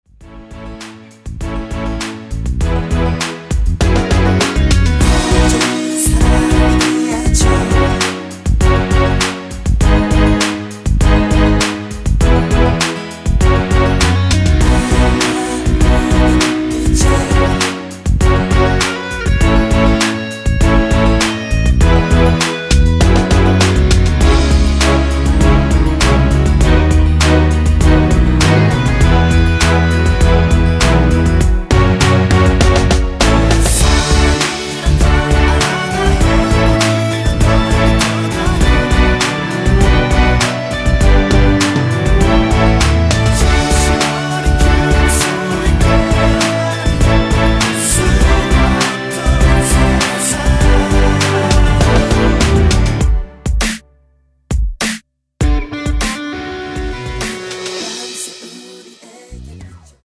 원키 코러스 포함된 MR입니다.(미리듣기 참조)
앞부분30초, 뒷부분30초씩 편집해서 올려 드리고 있습니다.